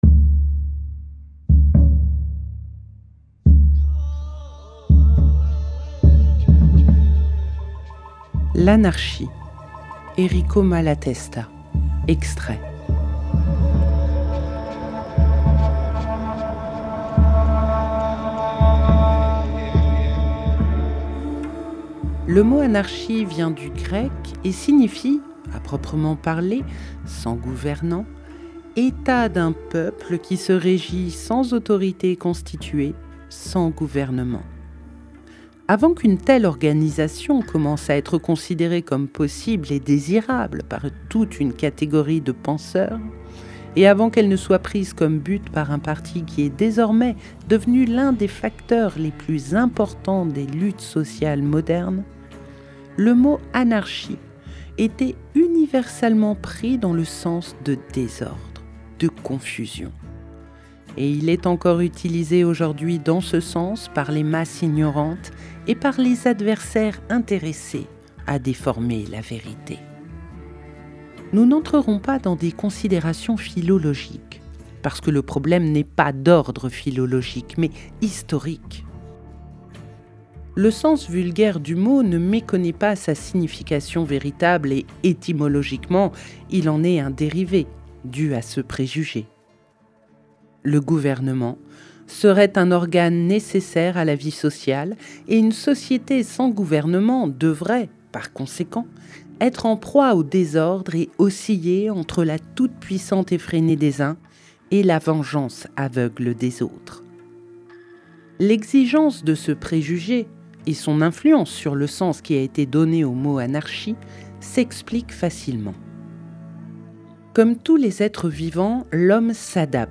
🎧 L’anarchie – Errico Malatesta - Radiobook